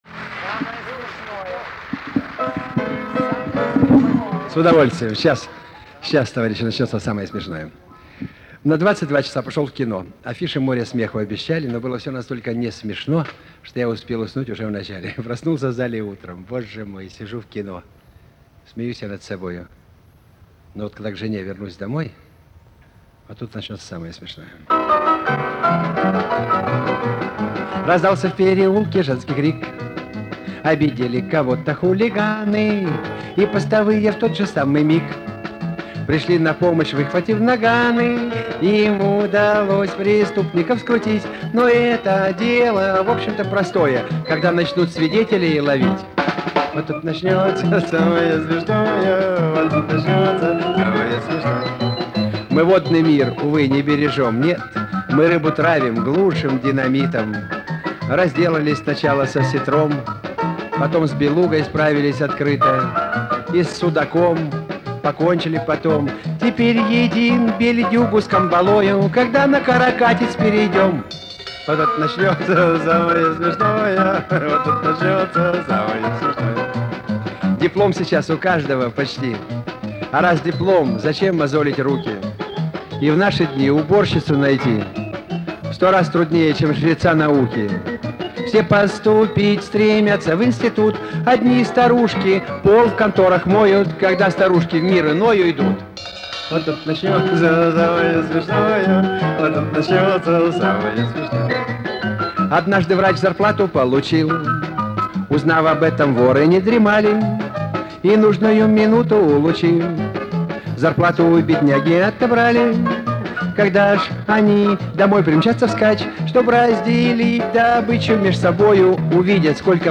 Помню пародию на песню АИСТ